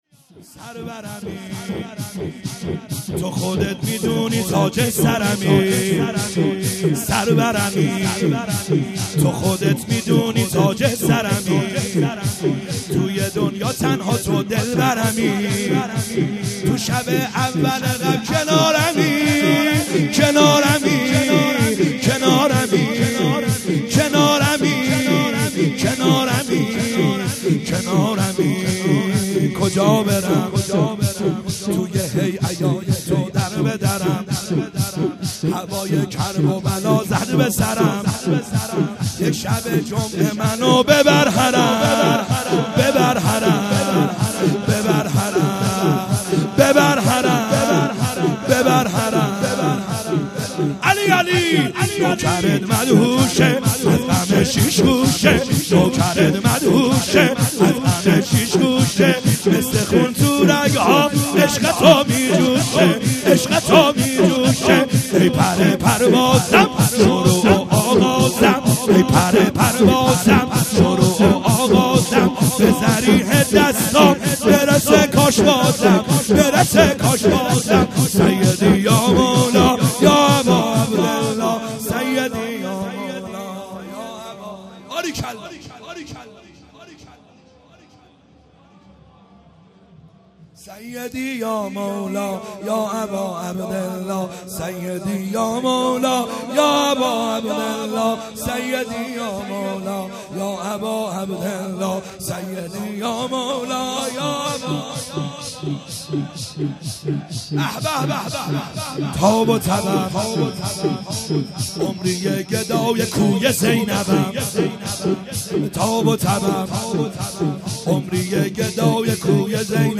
خیمه گاه - بیرق معظم محبین حضرت صاحب الزمان(عج) - شور | سرورمی تو خودت